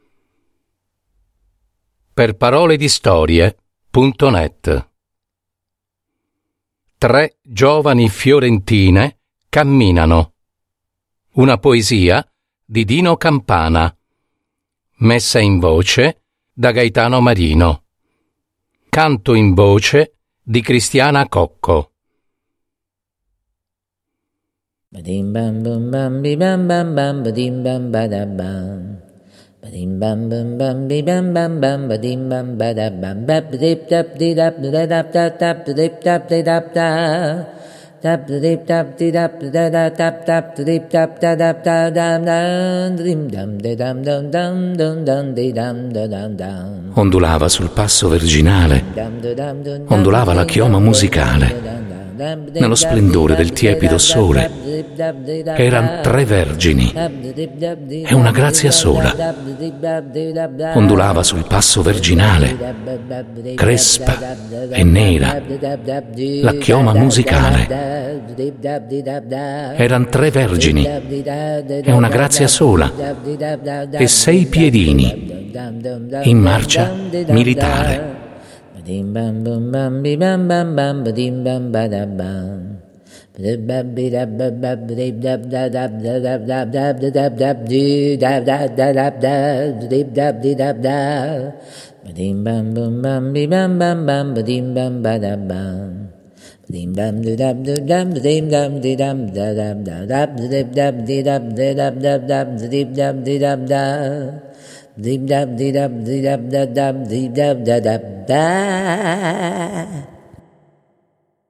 Canto in voce